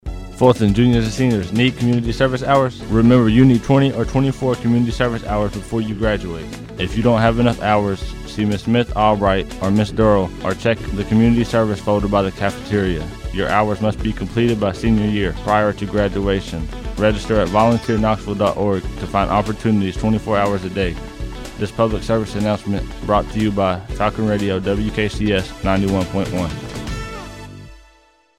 PSA encouraging FHS students to sign up for Community Service opportunities. Seniors must log 20-24 hours of Community Service before graduating.